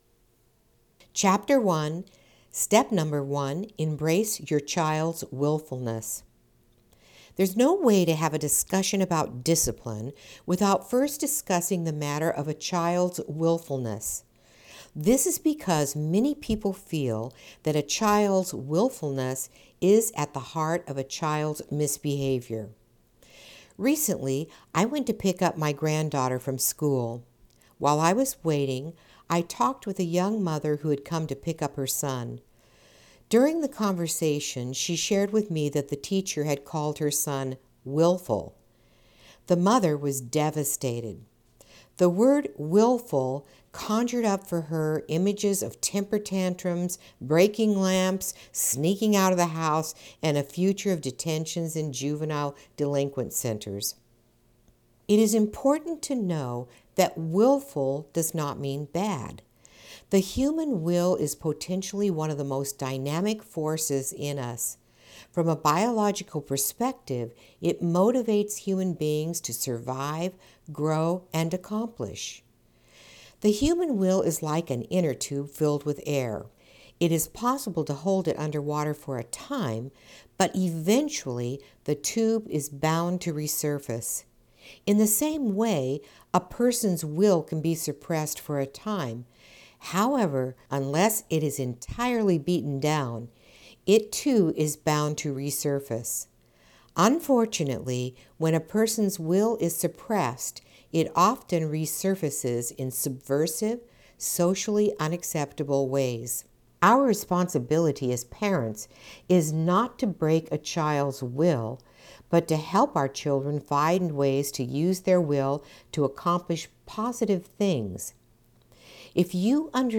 AUDIO BOOK ABOUT EFFECTIVE DISCIPLINE FOR PARENTS